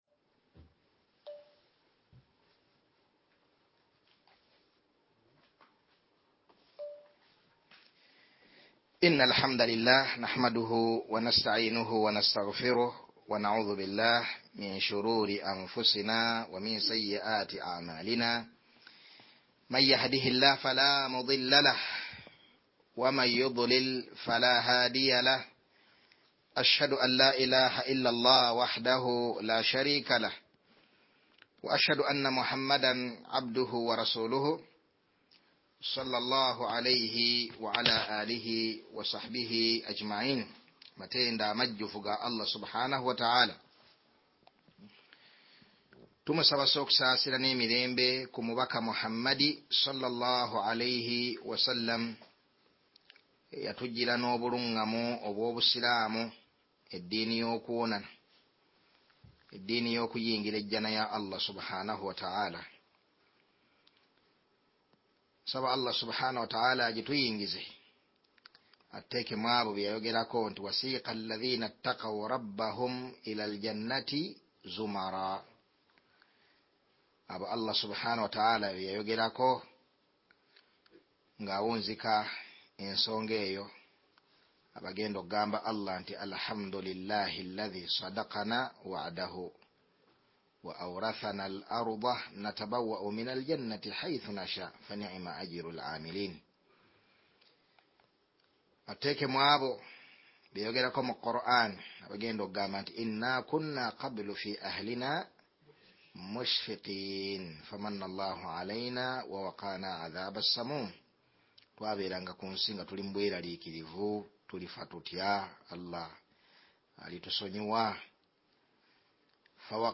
Wuliliza Emisomo gya ba ma sheikh be uganda